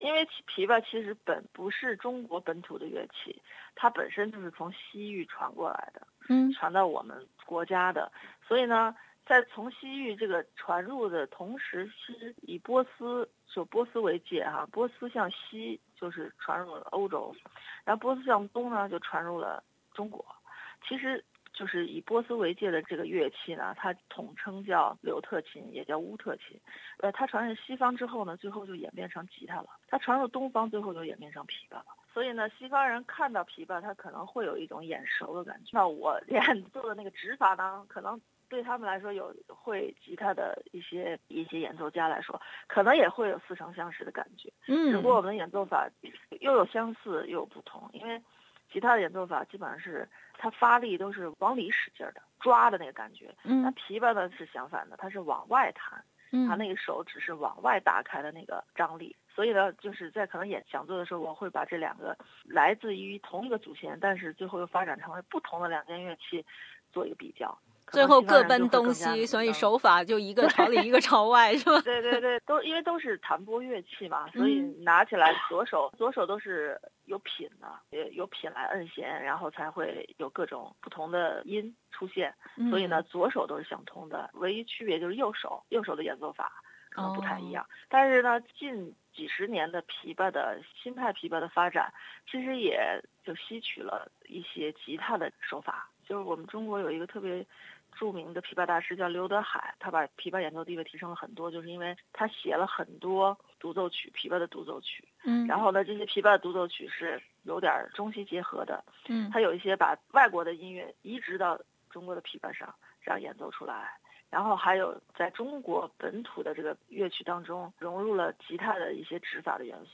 并接受本台采访